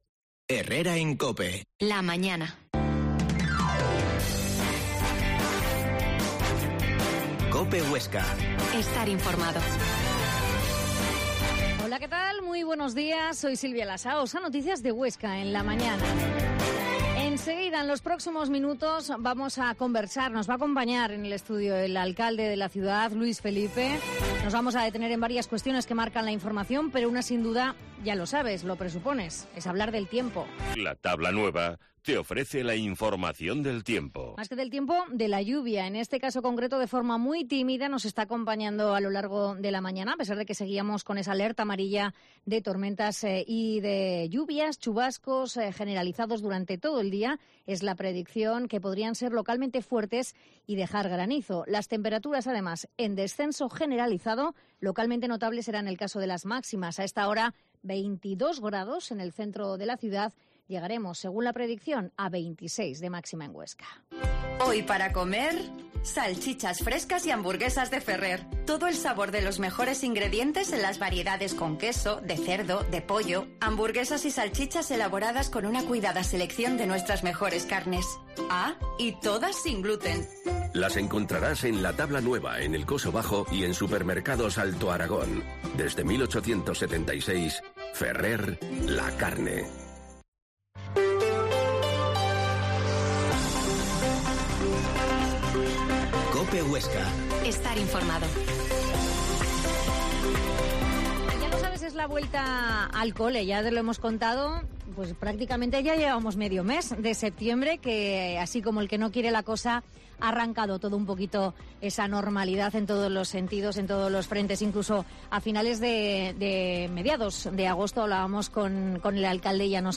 Herrera en COPE Huesca 12.50h Entrevista al alcalde Luis Felipe